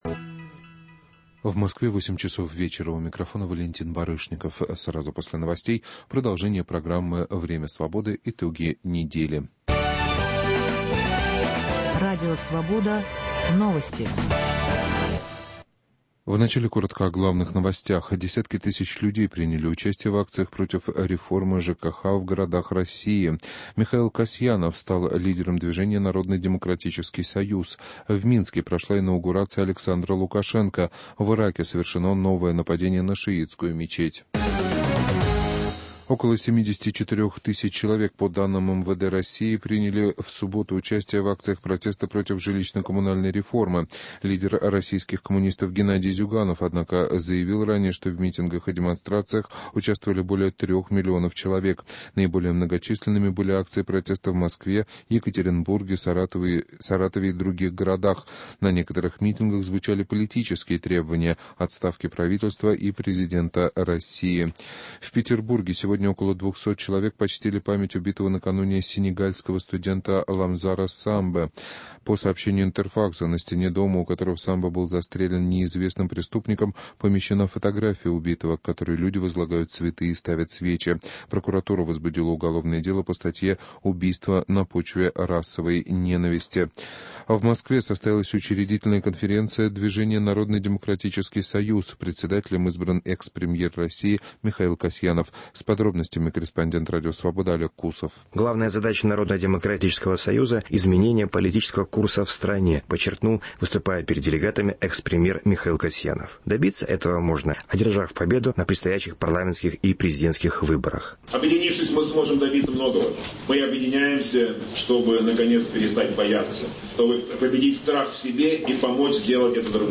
Беседа с экспертами о преступлениях на националистической почве, новости шоу-бизнеса, обзоры российских Интернет-изданий и американских еженедельников